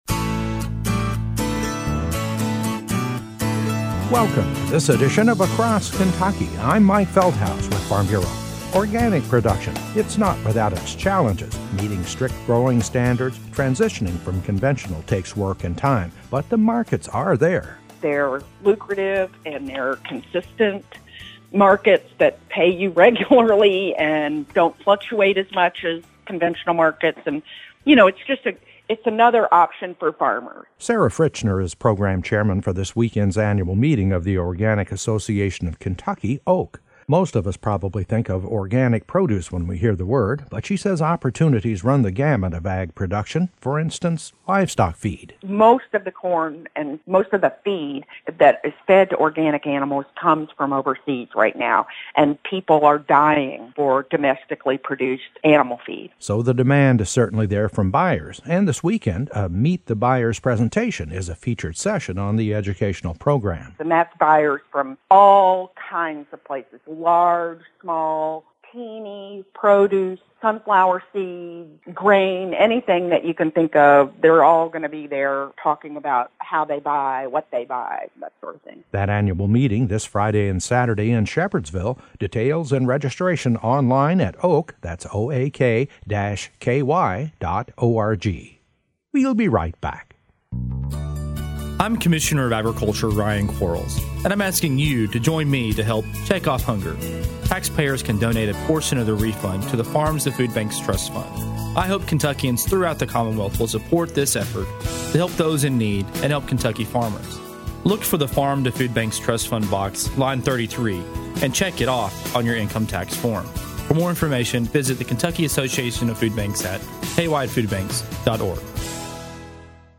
A report on one option for Kentucky farmers; going organic.  It’s not for everyone but it can offer farmers of all acreages an enterprise with strong markets and more predictable prices for their crops.